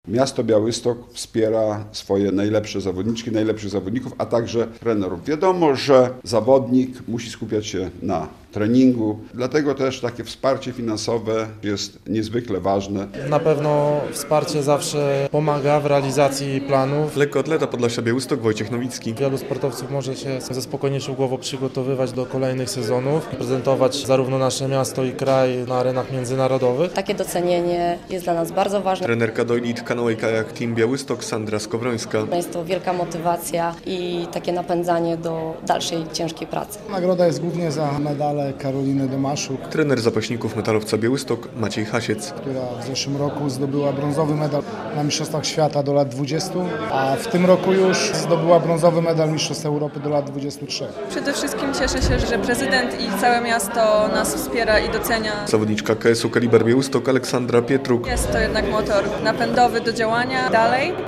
Lekkoatleci, zapaśnicy, karatecy, czy kajakarze i ich trenerzy odebrali w poniedziałek (19.05) Nagrody Prezydenta Białegostoku za wysokie wyniki sportowe w ubiegłym roku. Uroczystość miała miejsce w Pałacyku Gościnnym.
relacja